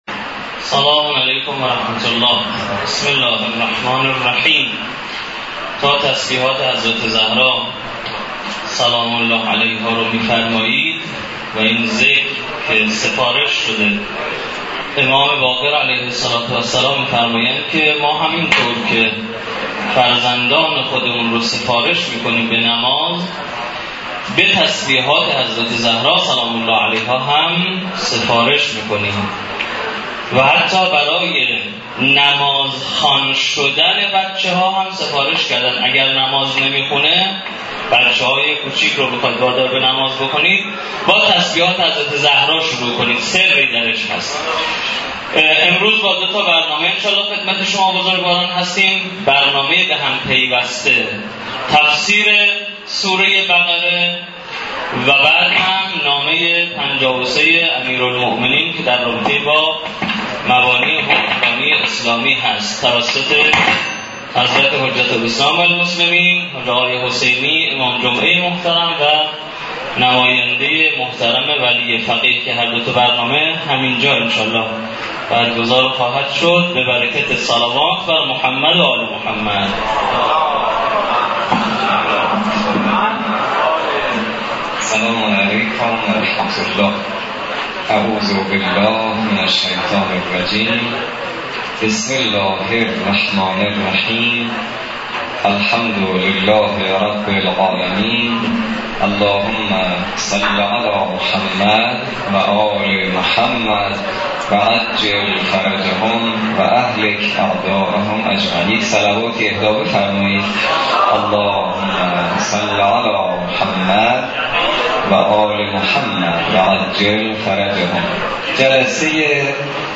جلسه سوم تفسیر سوره مبارکه بقره و بیستمین جلسه مباحثه نامه ۵۳ نهج البلاغه توسط نماینده محترم ولی فقیه و امام جمعه محترم کاشان در...